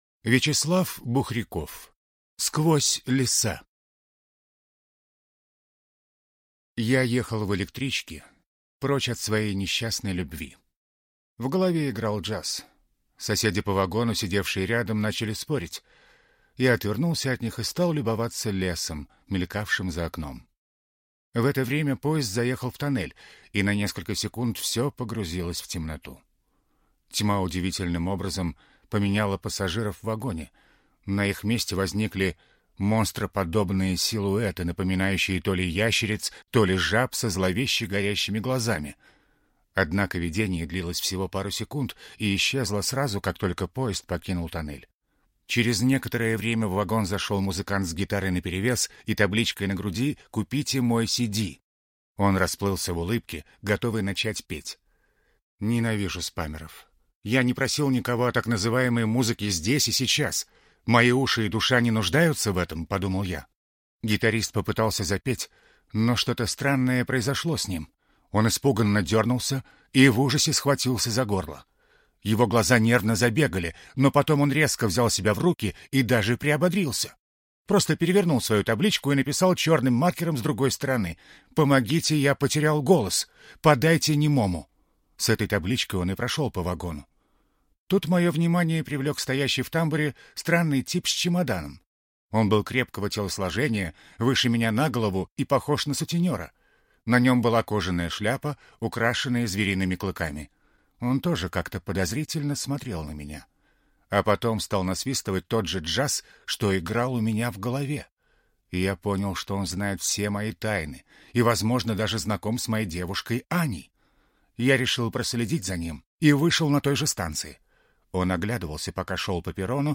Аудиокнига Сквозь леса | Библиотека аудиокниг
Прослушать и бесплатно скачать фрагмент аудиокниги